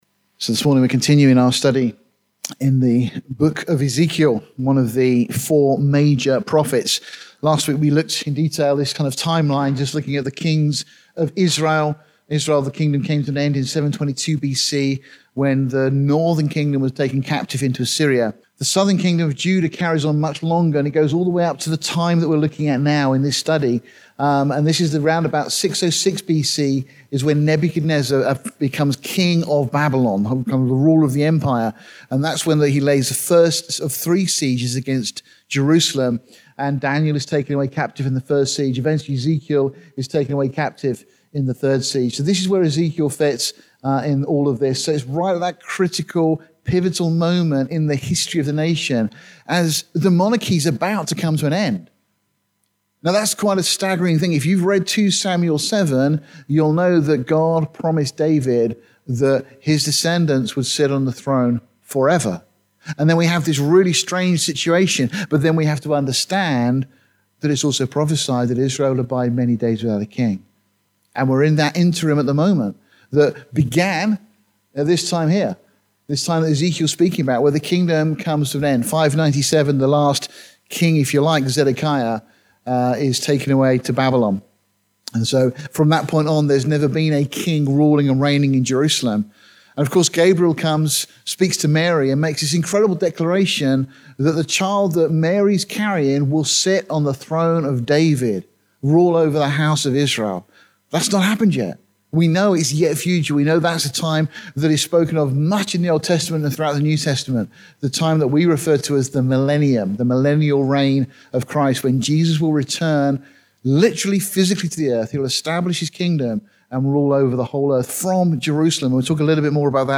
It is a transcript of the sermon.